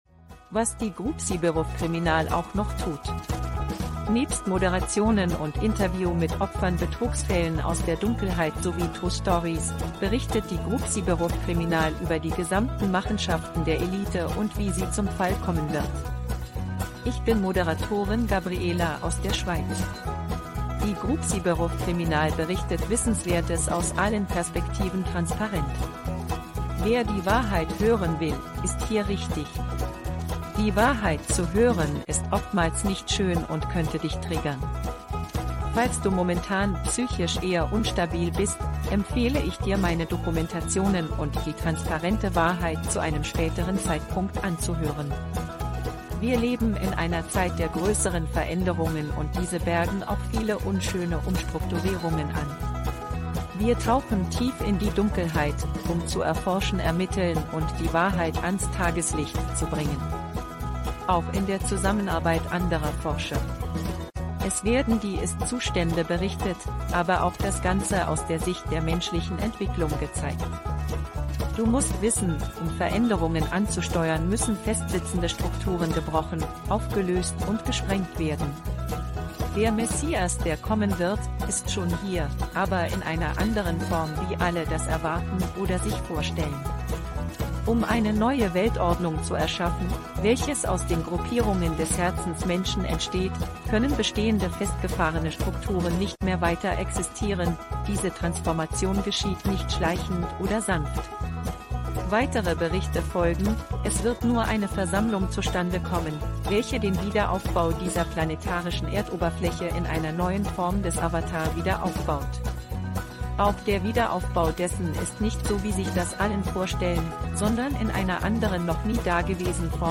Eine komplette neue Weltordnung – die einzigste Wahrheit frei von alten Strukturen alles Neu – Kurze – Vorfassung – mit Song